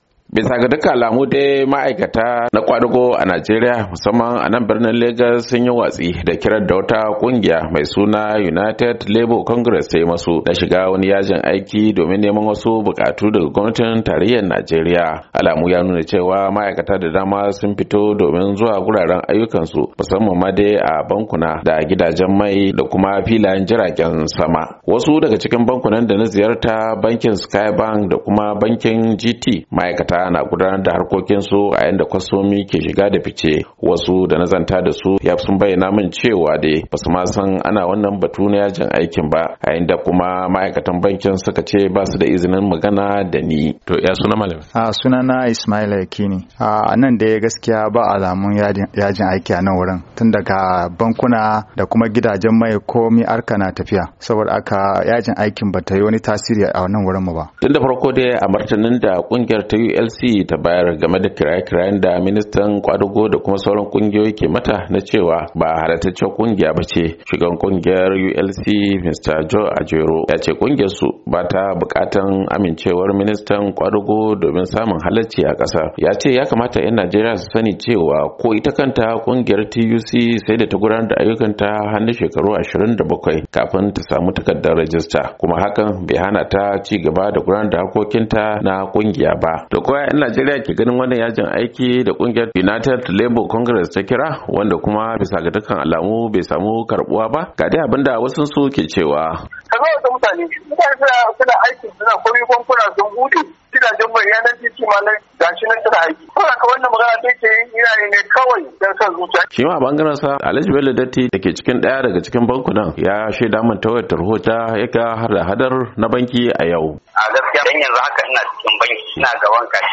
Ga rahoton